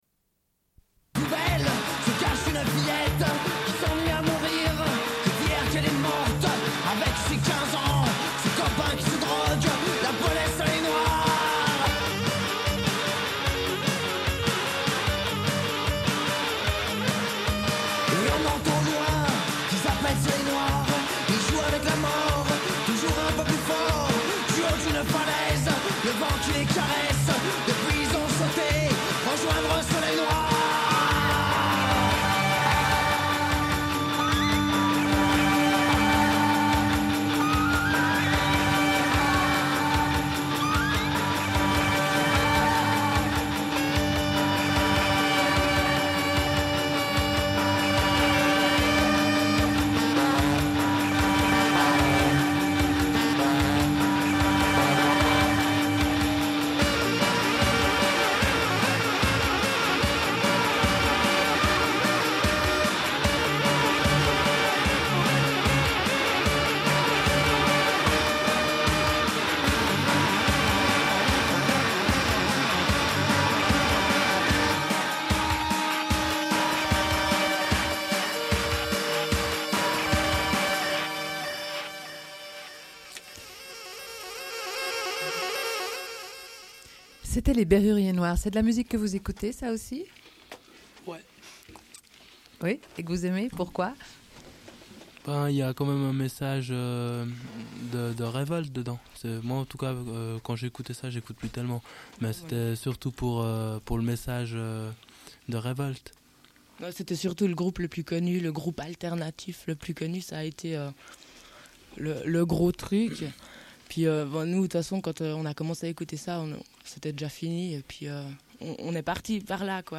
Une cassette audio, face A31:21
Radio